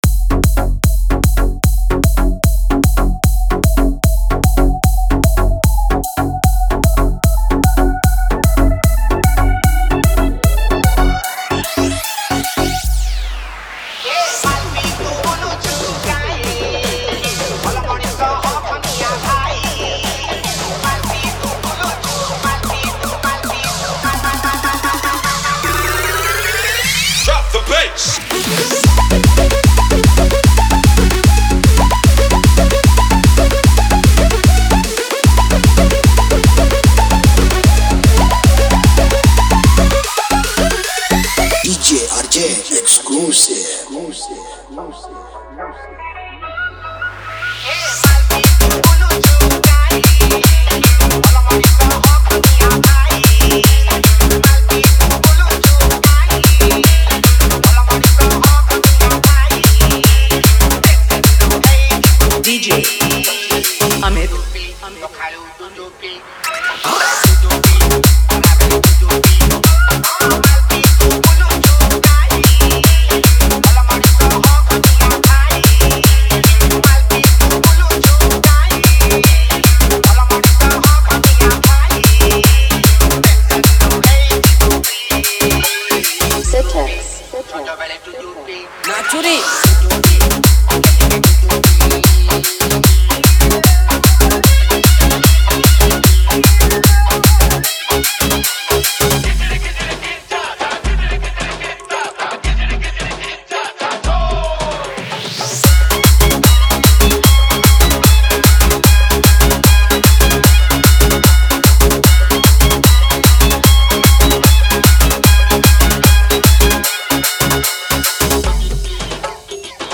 EDM DANCE MIX